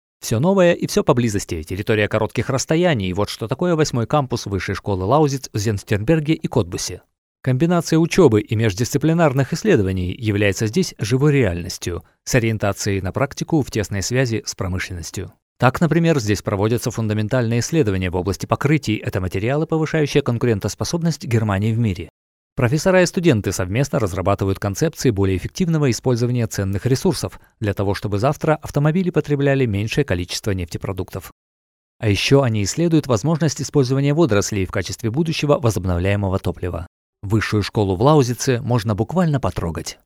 Friendly, plastic and artistic voice.
Sprechprobe: Industrie (Muttersprache):